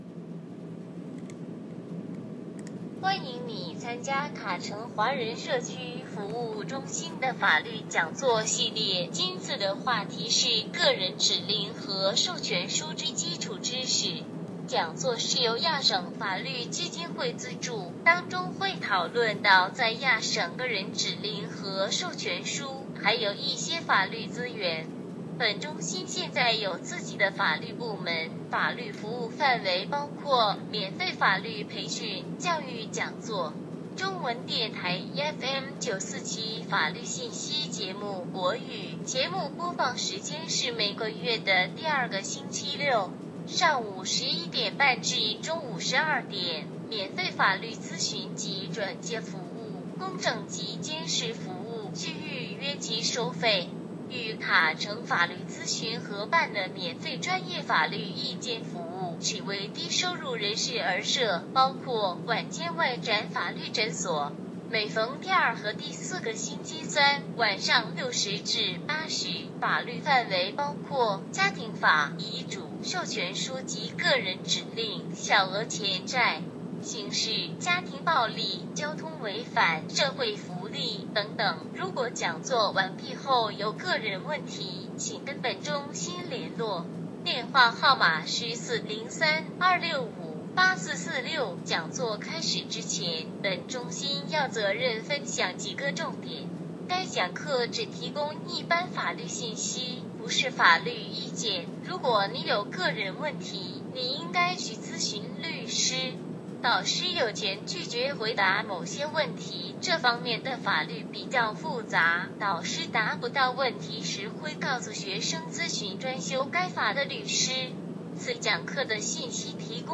This is an audio recording of the PD and PA presentation as well as the Wills presentation.
Type of Item Conference/Workshop Presentation